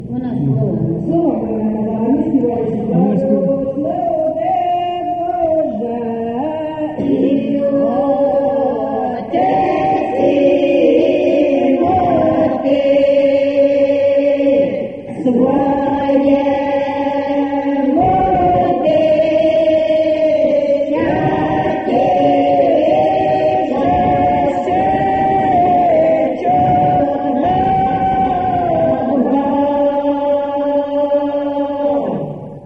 ЖанрВесільні
Місце записус. Веселий Поділ, Семенівський район, Полтавська обл., Україна, Полтавщина